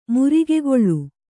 ♪ murigegoḷḷu